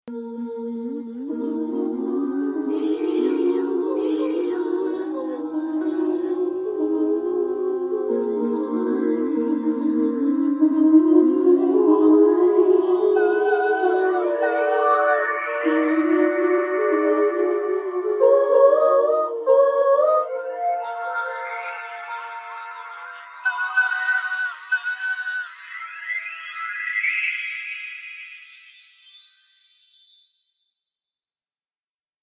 جلوه های صوتی
برچسب: دانلود آهنگ های افکت صوتی طبیعت و محیط دانلود آلبوم صدای محیط از افکت صوتی طبیعت و محیط